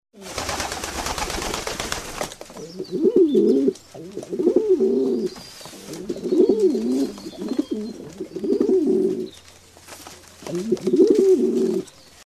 Звуки отпугивания голубей